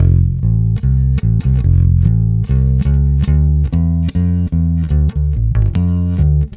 Twisting 2Nite 5 Bass-F.wav